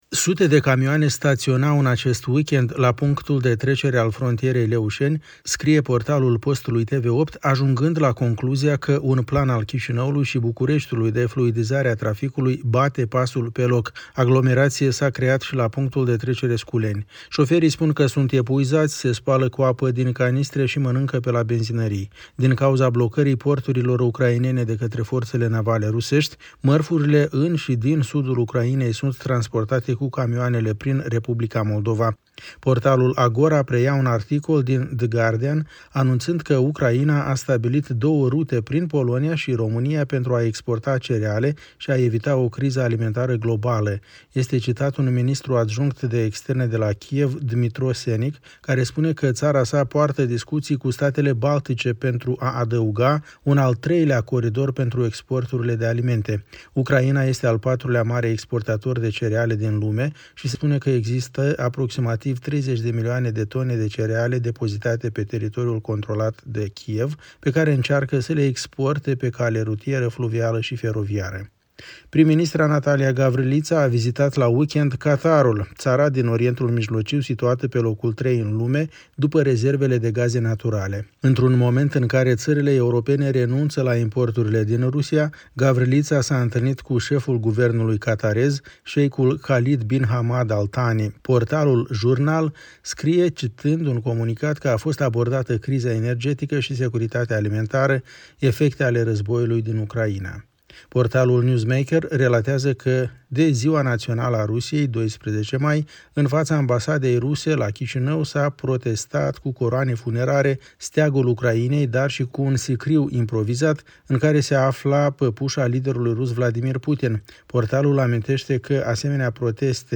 Ştirile presei moldovene